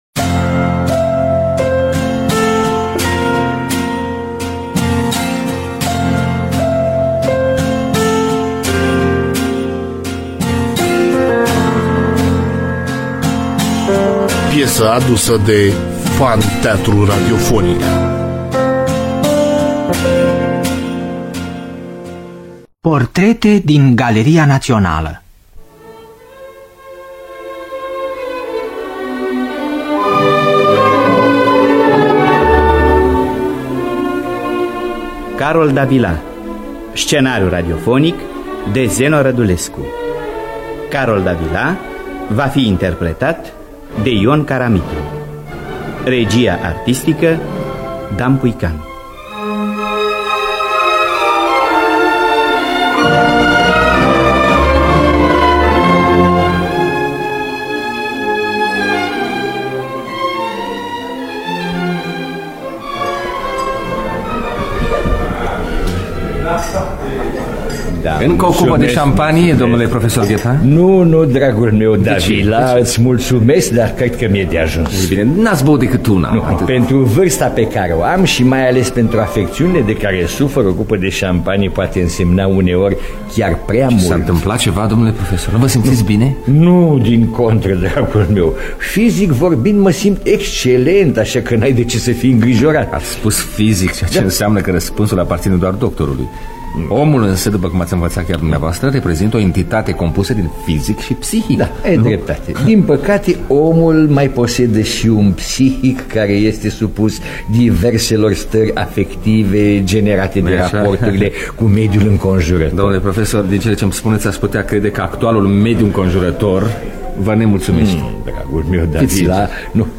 Biografii, Memorii: Carol Davila (1978) – Teatru Radiofonic Online